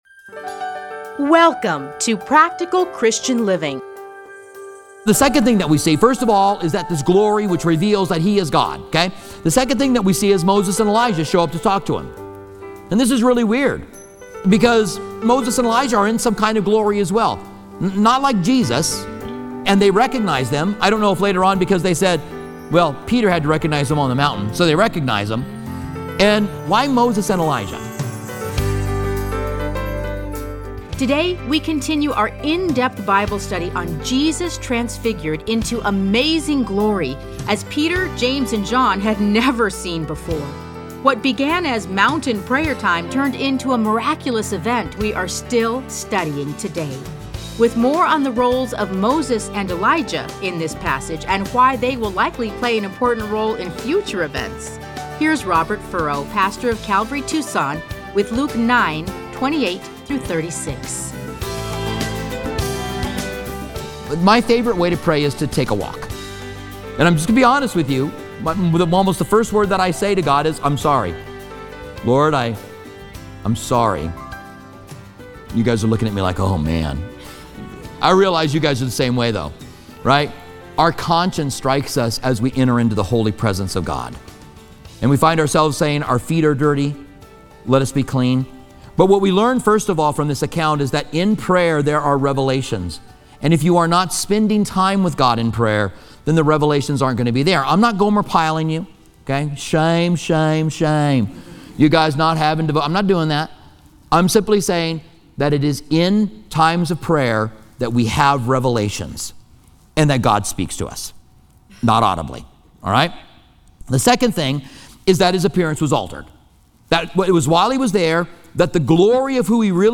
Listen to a teaching from Luke 9:28-36.